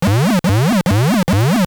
trhq_alarm.wav